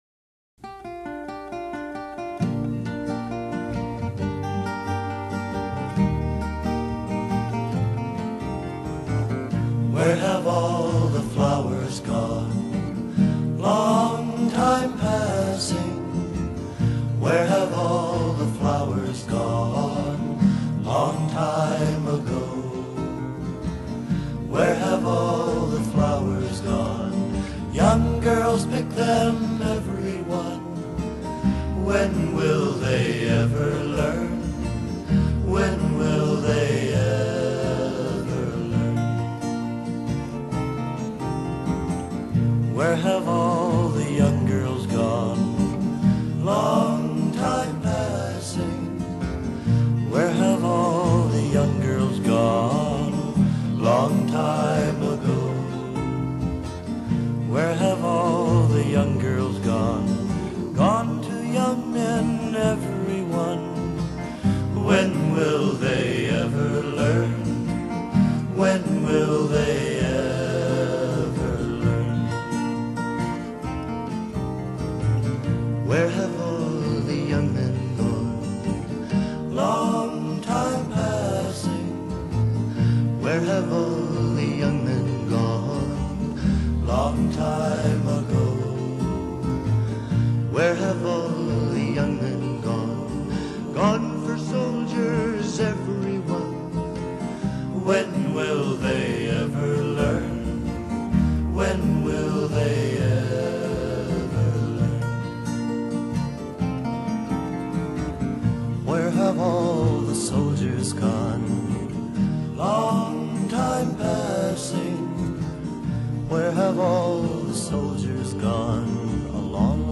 Genre : Pop, Oldies